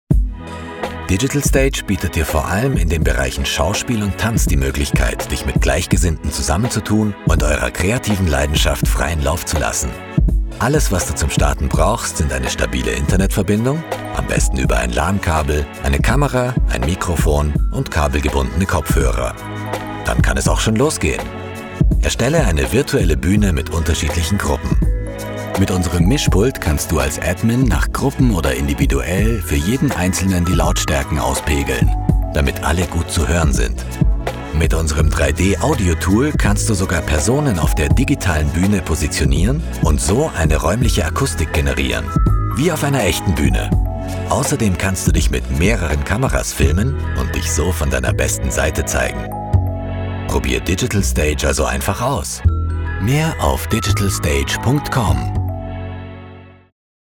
Male
Assured, Authoritative, Confident, Cool, Corporate, Deep, Friendly, Natural, Reassuring, Smooth, Warm, Approachable, Character, Cheeky, Conversational, Energetic, Engaging, Funny, Gravitas, Sarcastic, Streetwise, Upbeat, Versatile, Witty
bavarian, viennese
Documentary_Narrator_epic_nature_Ger.mp3
Microphone: Neumann U87
Audio equipment: RME Babyface Pro, SPL Track one, Studiobricks Booth, Neumann KH 120, Source Connect, Zoom